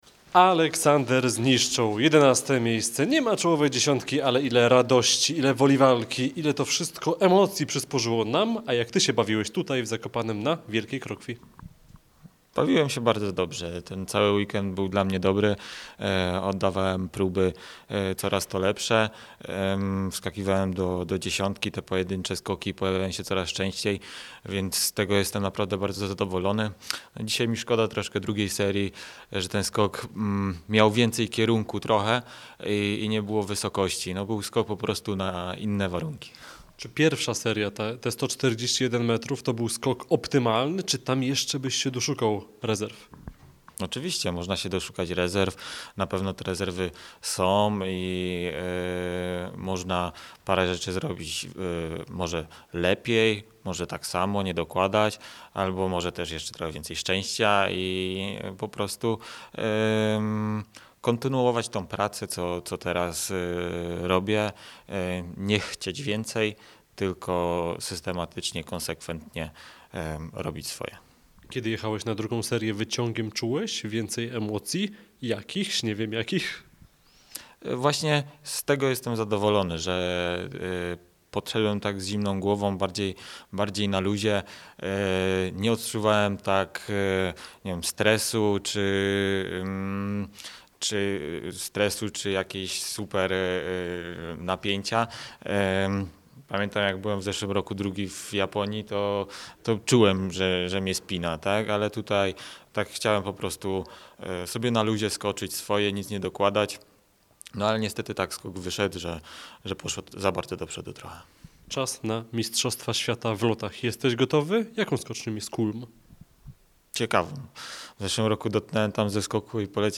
Wskakiwałem też do dziesiątki w pojedynczych seriach, z czego jestem bardzo zadowolony – mówił po konkursie polski sportowiec.
Aleksander-Zniszczol-po-zawodach-w-Zakopanem.mp3